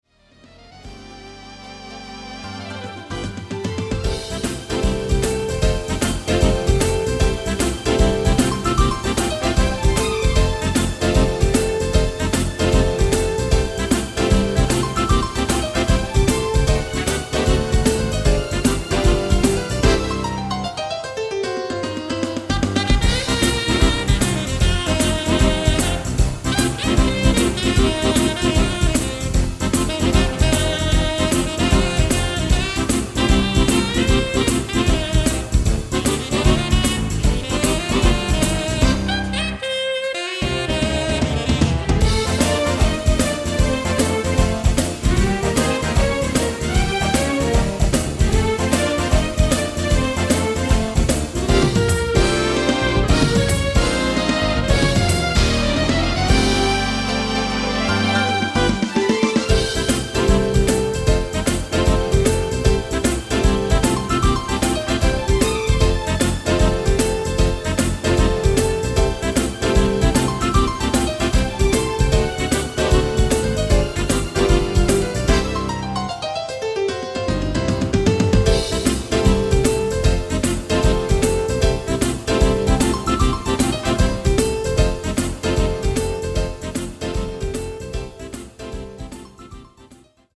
Boogie-Woogie